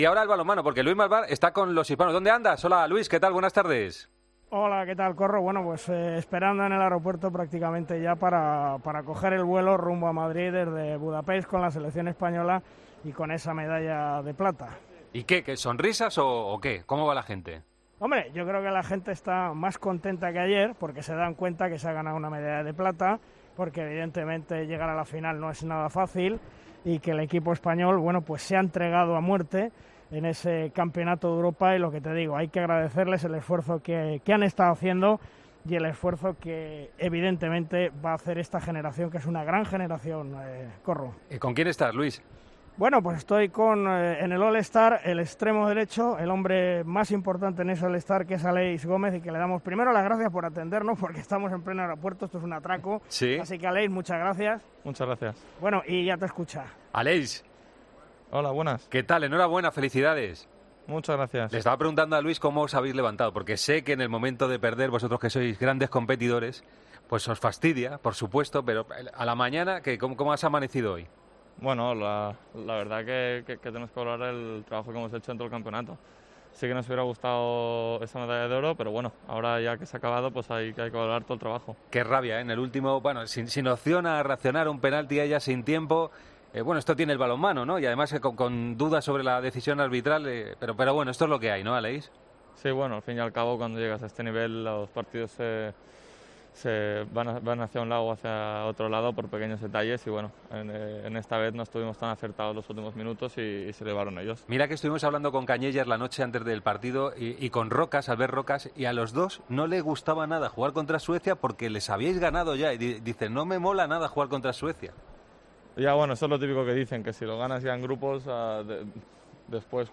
El mejor extremo derecho del último Europeo de balonmano valora en Deportes COPE la medalla de plata conseguida tras haber perdido ante Suecia en la final.